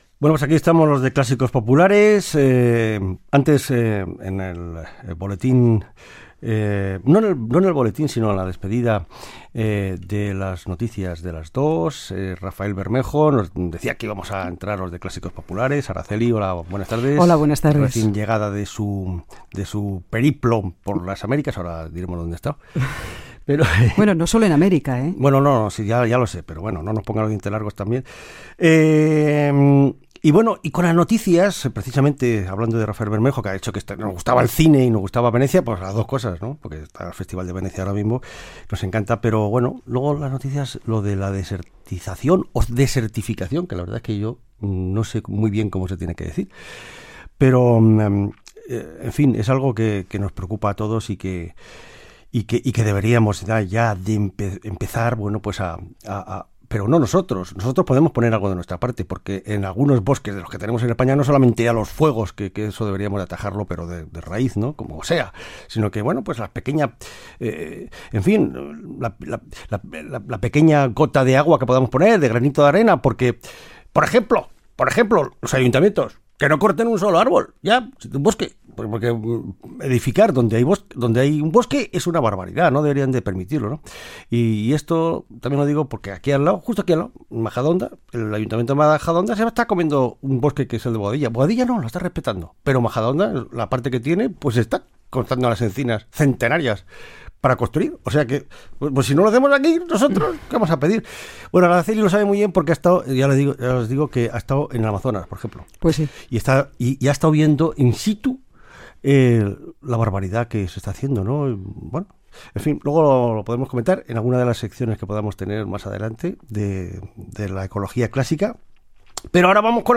Salutació, comentari sobre la desertització, tema musical, presentació de dos tema
Musical